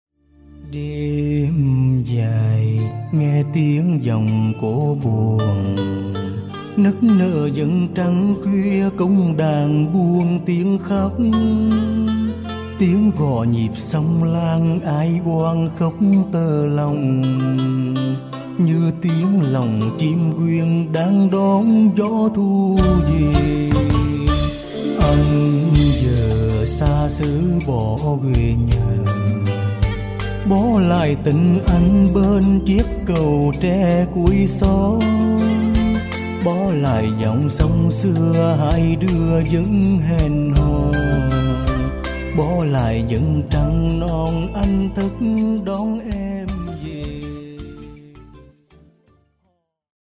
Bolero/ Trữ tình